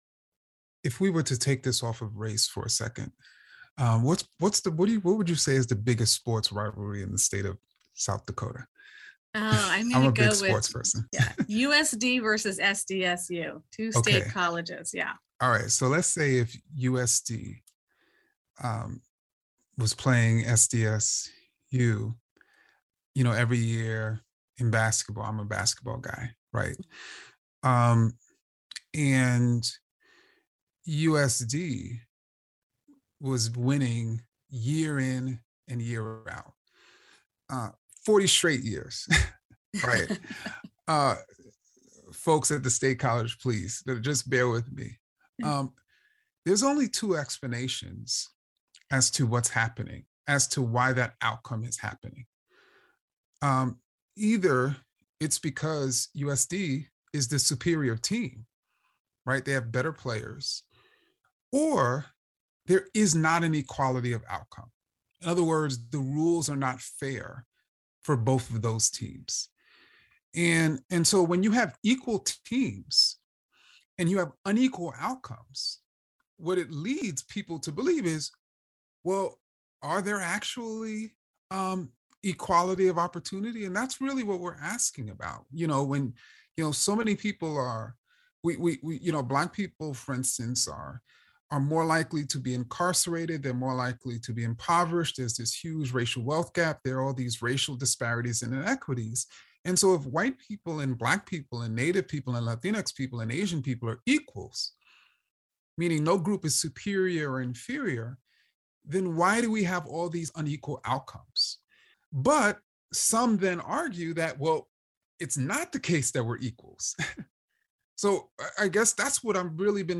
Ibram X. Kendi joins us to talk about his focus on policy outcomes instead of intentions. In the Moment is SDPB’s daily news and culture magazine program.
In the Moment features authentic conversations with newsmakers, scholars, artists, and everyday South Dakotans.